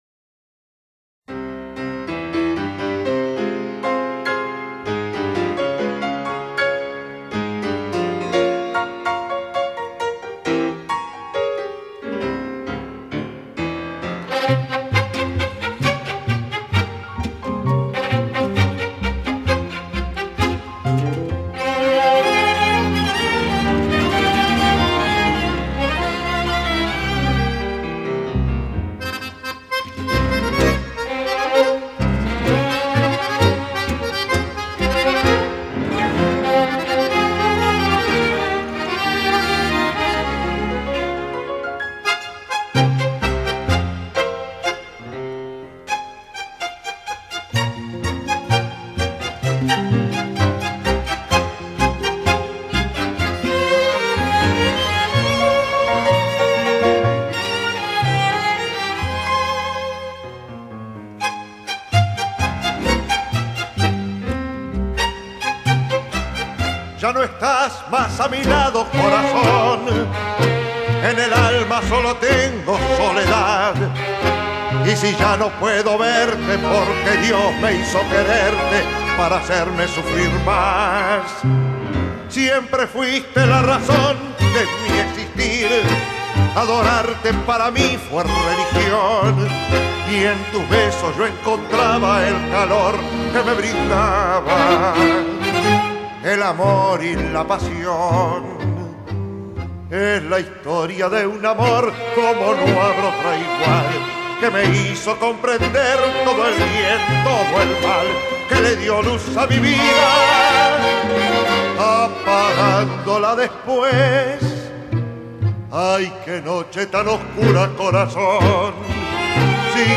strumental version